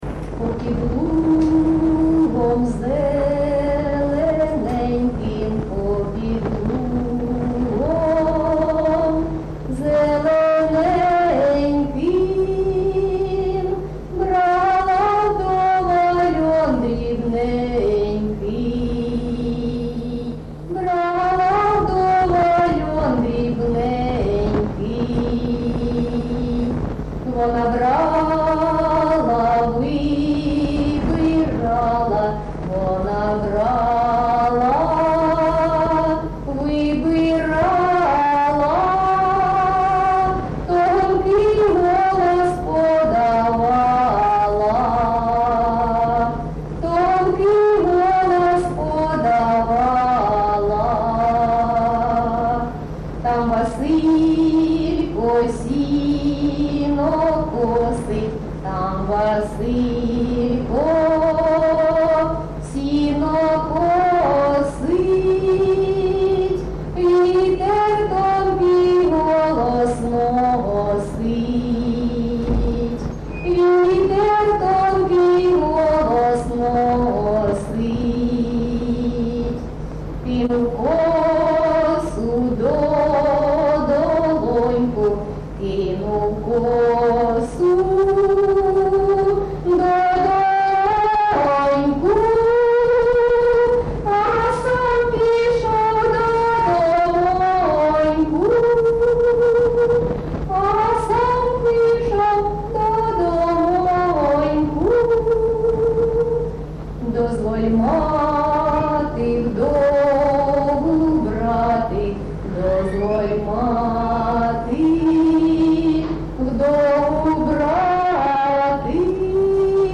Місце записус. Євсуг, Старобільський район, Луганська обл., Україна, Слобожанщина